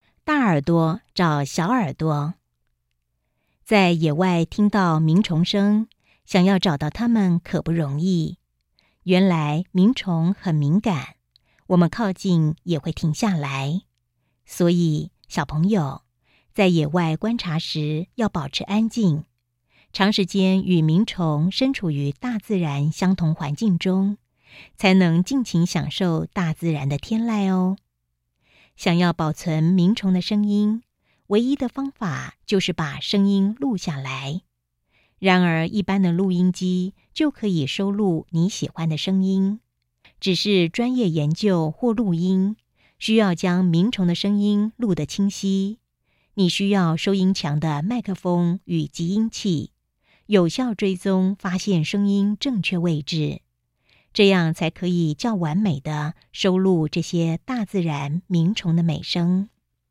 語音導覽 000:00:00 下載